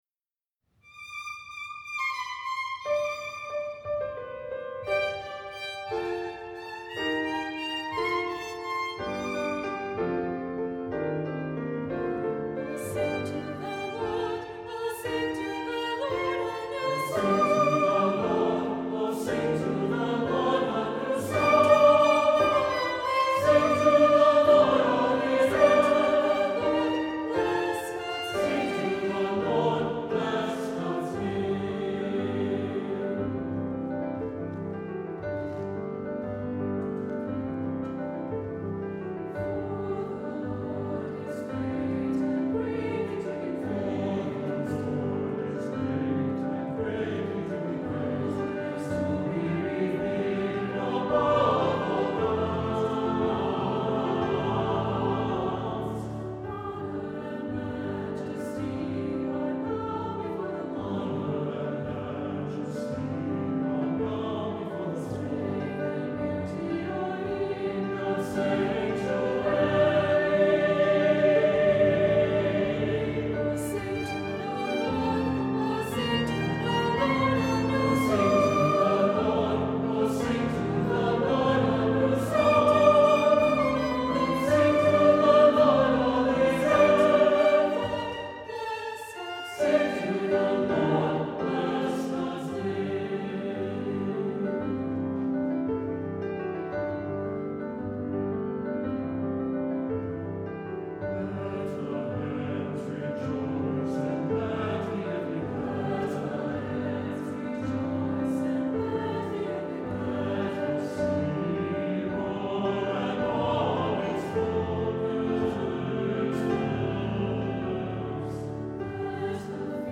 Voicing: SATB and Children's Choir